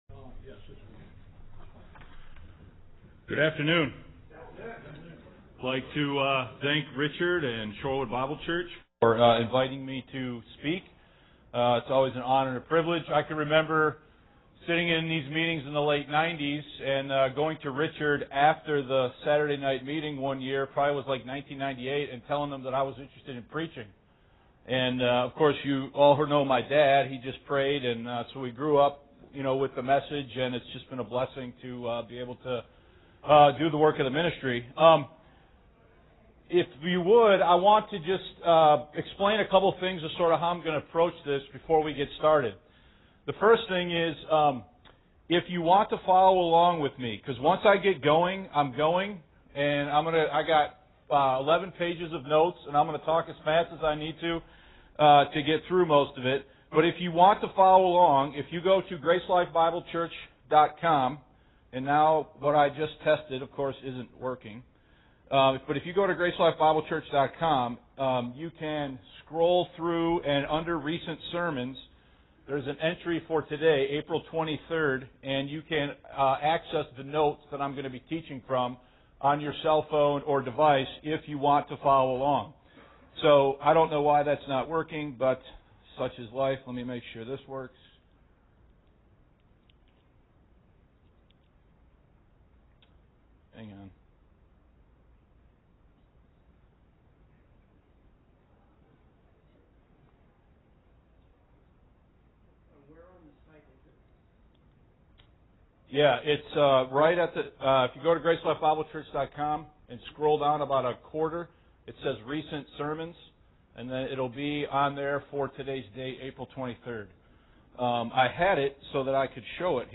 Why Universal Reconciliation Is a Dangerous Heresy (GSB Pastors Conference)